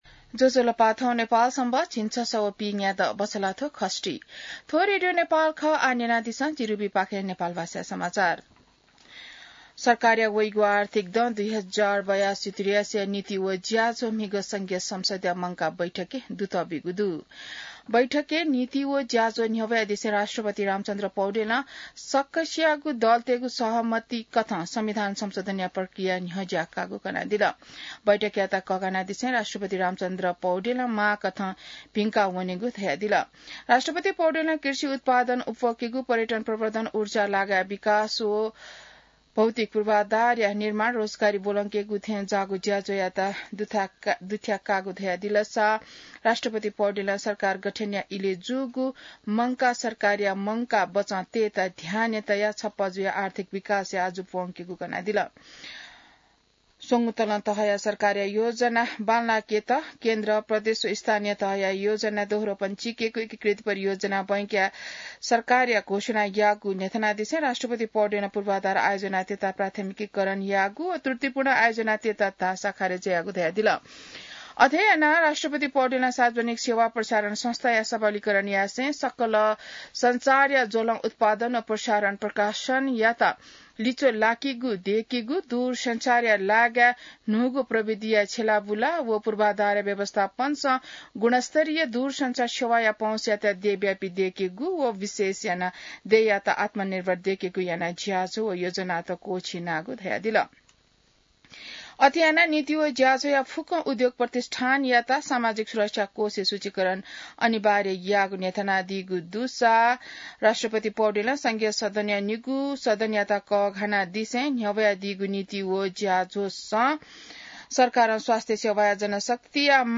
नेपाल भाषामा समाचार : २० वैशाख , २०८२